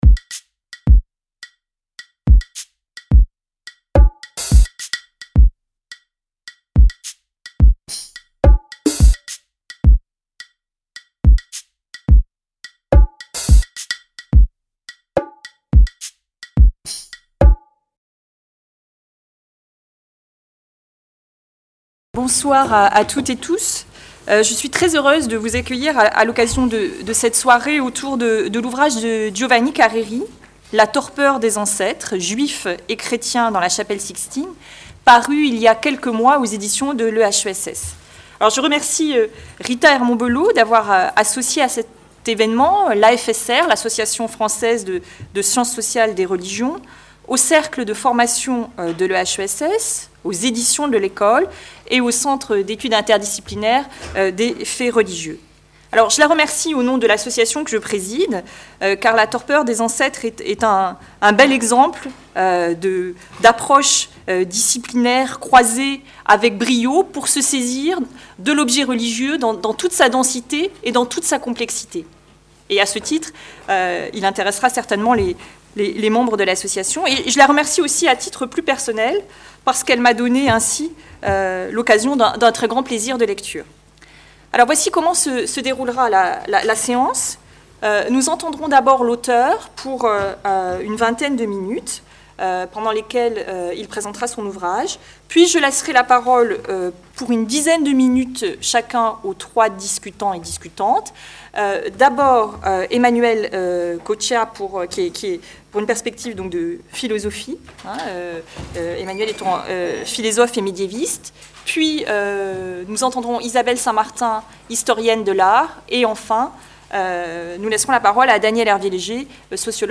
Conférence-débat organisée par les Cercles de formation de l'EHESS, les Éditions de l'EHESS, l’AFSR et le CEIFR.